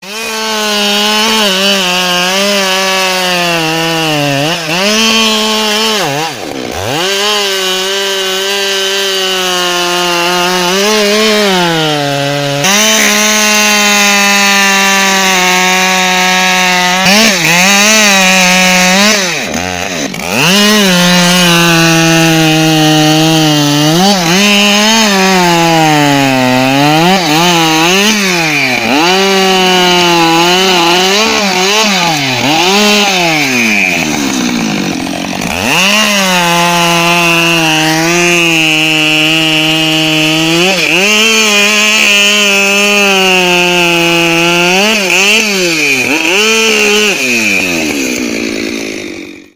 Motorsäge Husqvarna Klingelton
Mit diesem Klingelton erleben Sie den kraftvollen und markanten Sound eines echten Motorsägen-Motors – ideal für alle, die etwas Besonderes suchen.
motorsaege-husqvarna-klingelton-de-www_tiengdong_com.mp3